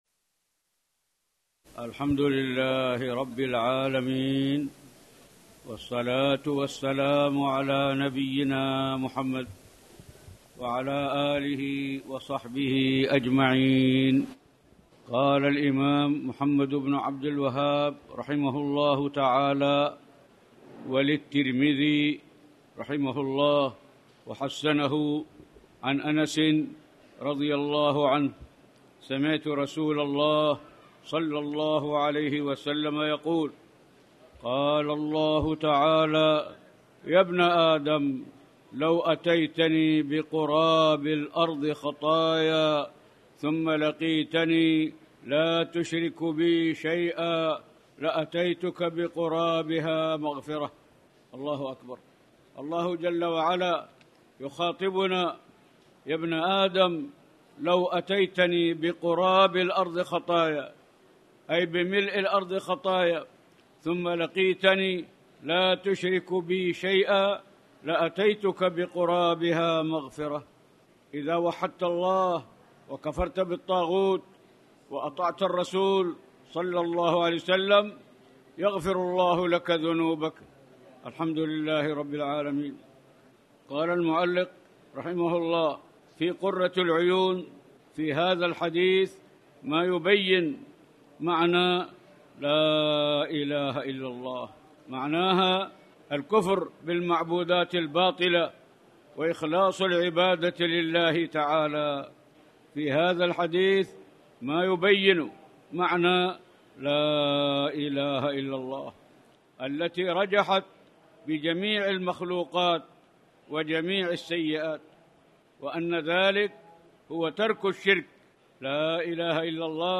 تاريخ النشر ٢٨ ذو القعدة ١٤٣٨ هـ المكان: المسجد الحرام الشيخ